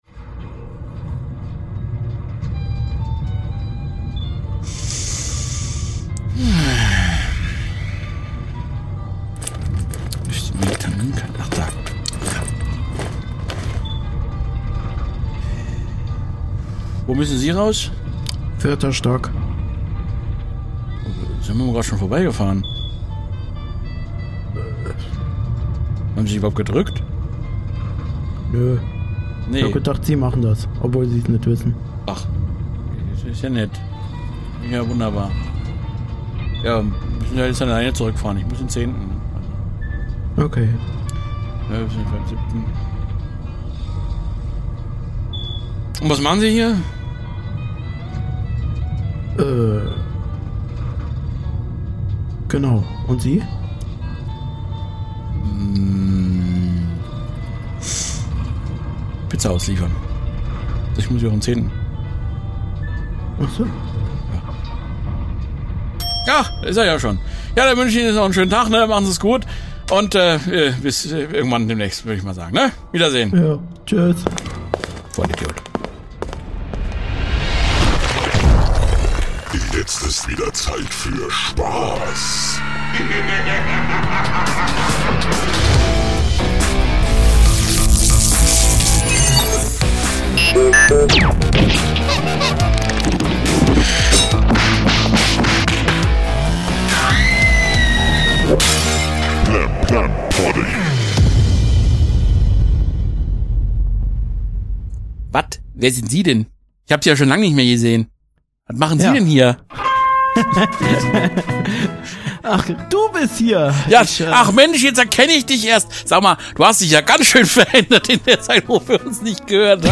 Daher quatschen sie erstmal, was ihnen währenddessen so passiert ist.